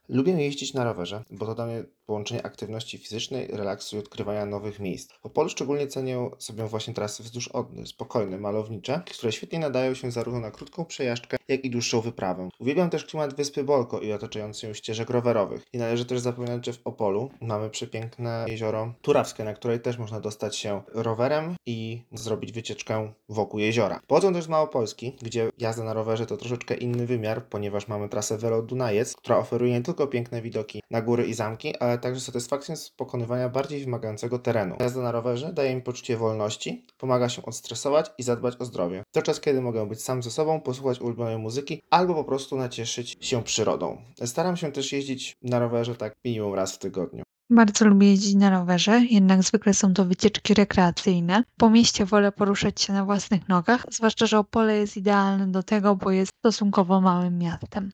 Coraz więcej osób – także studentów – wybiera go jako codzienny środek transportu. Posłuchajmy czy studenci lubią jeździć na dwóch kółkach: